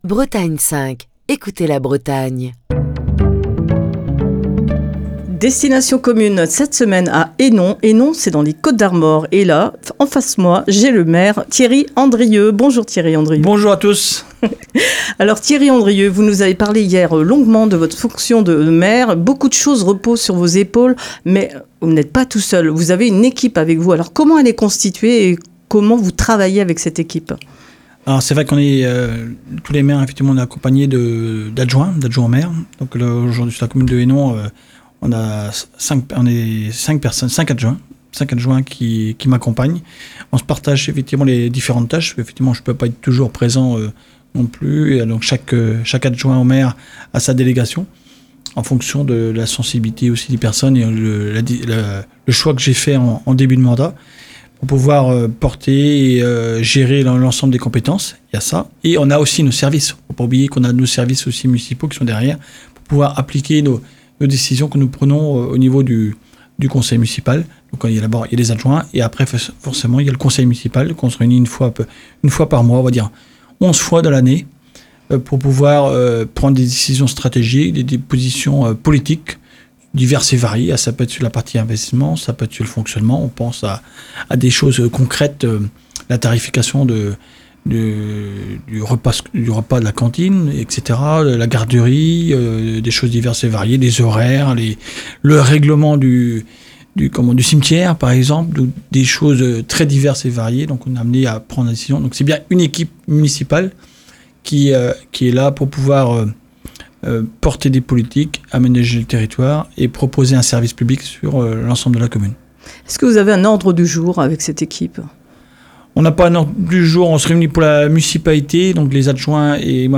Thierry Andrieux, maire de Hénon, qui nous présente sa commune